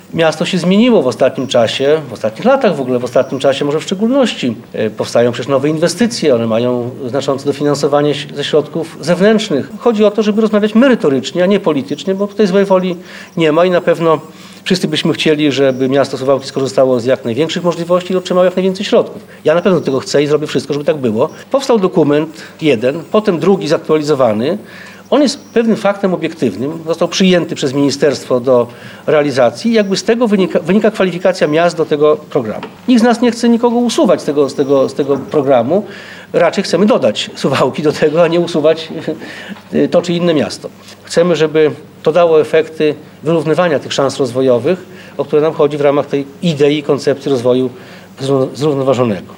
Władze miasta o interwencję w tej sprawie poprosiły posła Jarosława Zielińskiego, który wziął udział w sesji Rady Miejskiej.